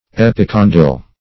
Epicondyle \Ep`i*con"dyle\, n. [Pref. epi- + condyle.]